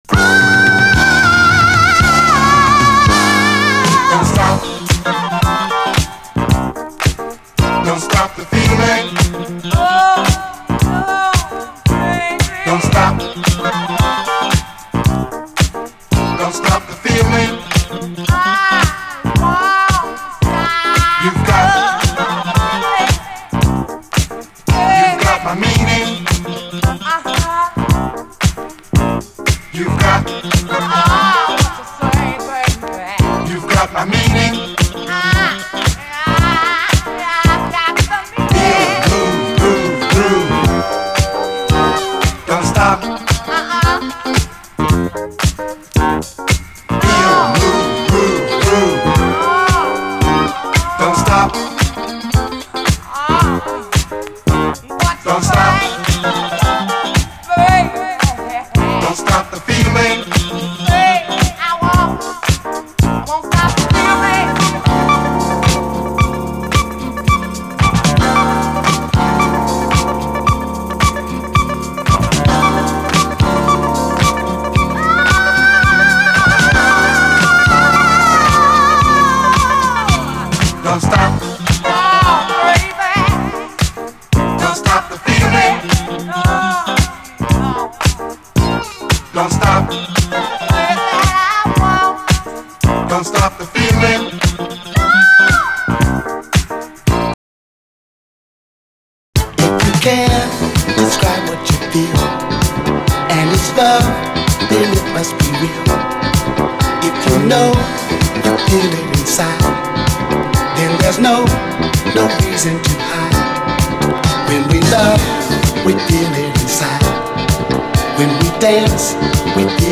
SOUL, 70's～ SOUL, DISCO, 7INCH